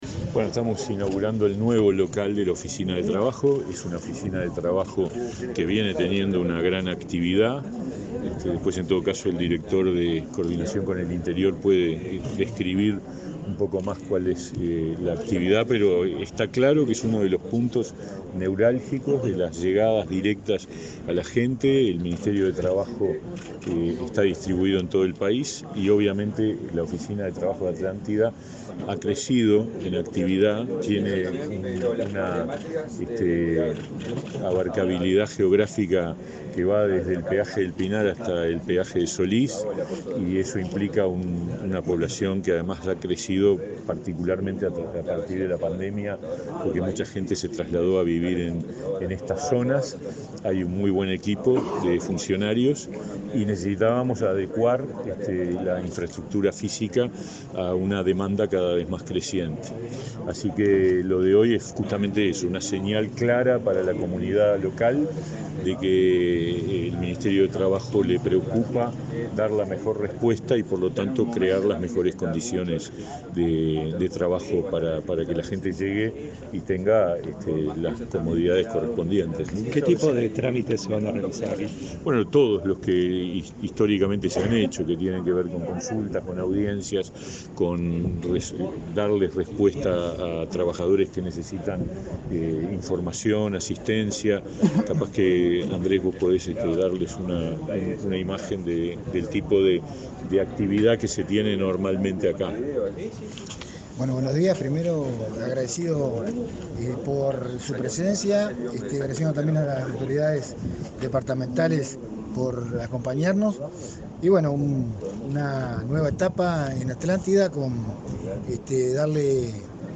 Declaraciones a la prensa de autoridades del Ministerio de Trabajo
Declaraciones a la prensa de autoridades del Ministerio de Trabajo 24/05/2022 Compartir Facebook X Copiar enlace WhatsApp LinkedIn Este martes 24, el Ministerio de Trabajo inauguró una oficina en Atlántida, departamento de Canelones, y, luego, el titular de esa cartera, Pablo Mieres; la directora general de Secretaría, Victoria Arregui, y el director nacional de Coordinación en el Interior, Andrés Prati, dialogaron con la prensa.